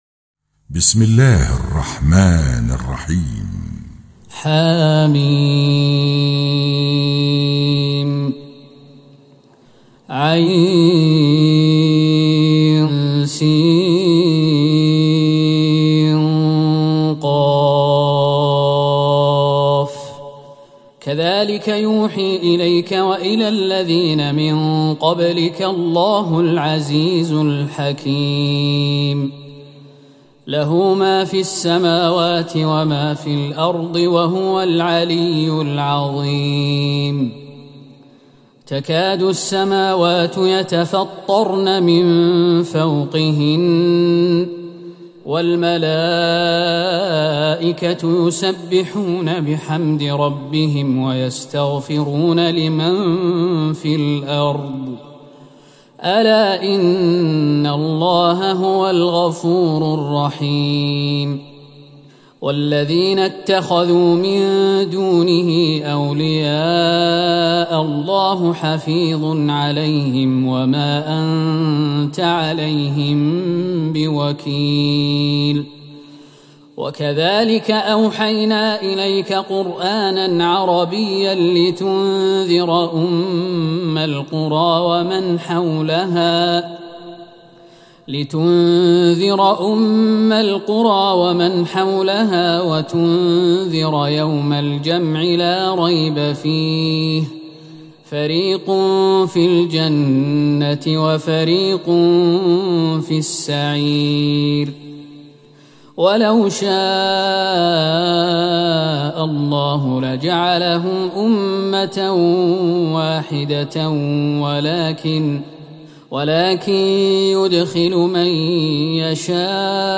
تهجد ٢٦ رمضان ١٤٤١هـ سورة الشورى كاملة > تراويح الحرم النبوي عام 1441 🕌 > التراويح - تلاوات الحرمين